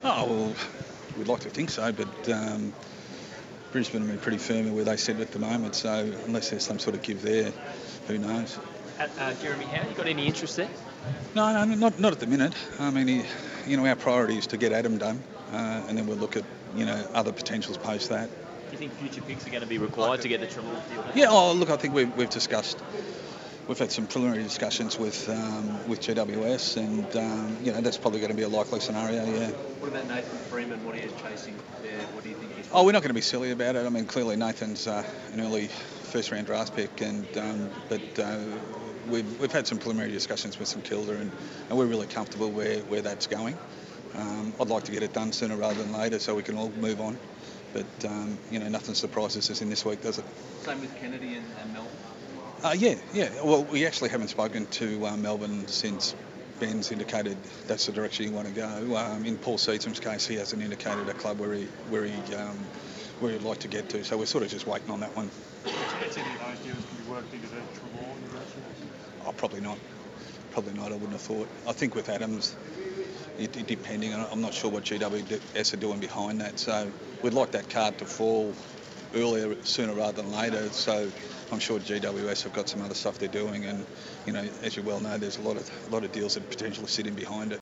addressed the media on the first day of the AFL Trade Period.